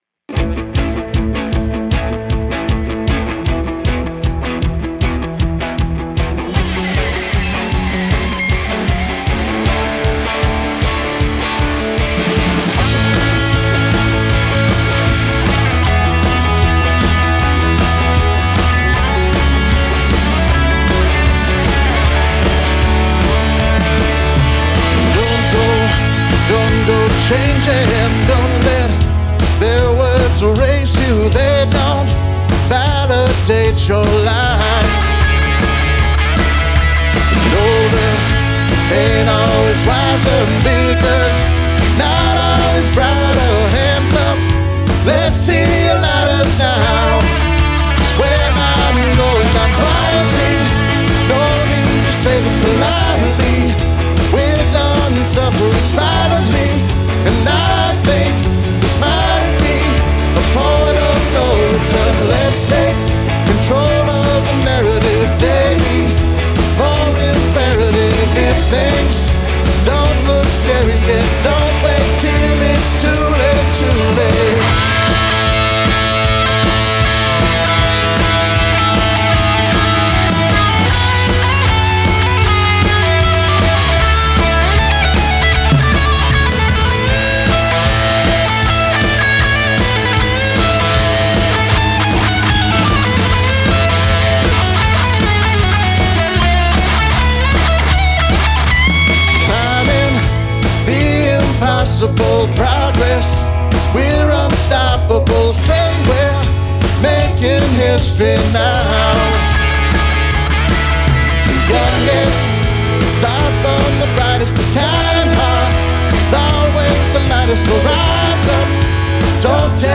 Lead Vocals, Bass, Guitars
Lead Guitar
Backing Vocals